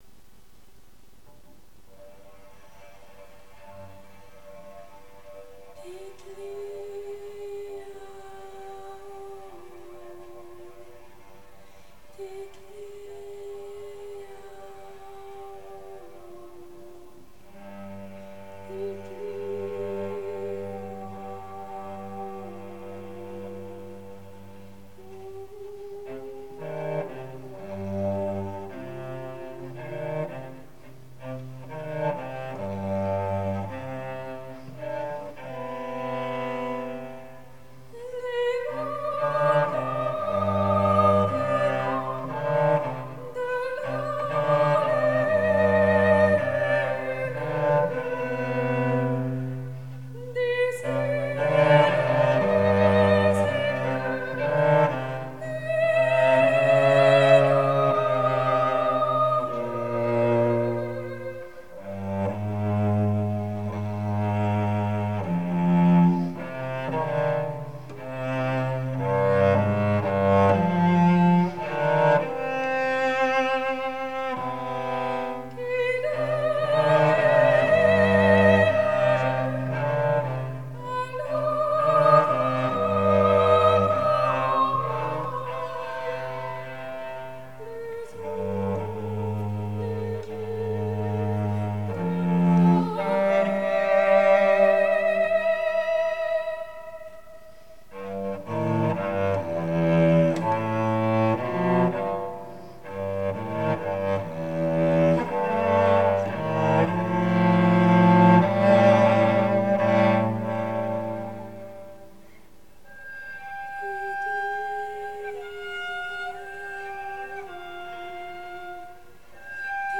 Comptines, 3 pièces pour voix et violoncelle.